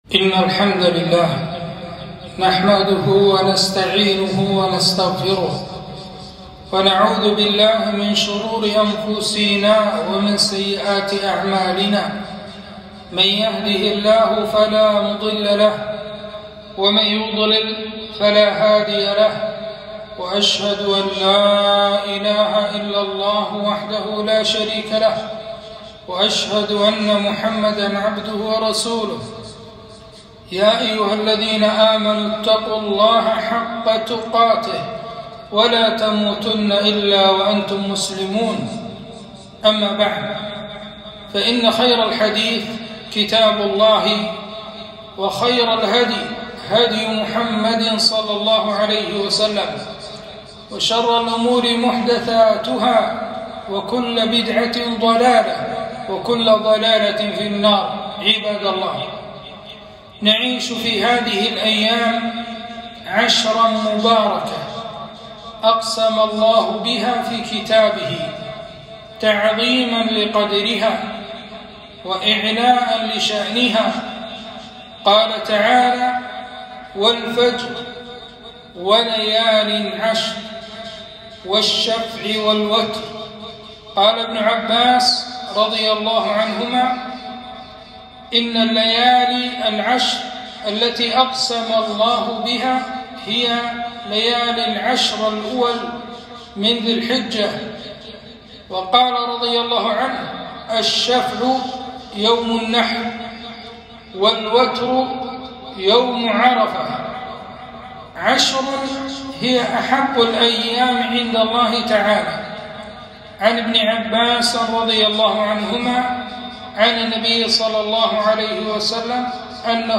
خطبة - فضل يوم عرفة ويوم النحر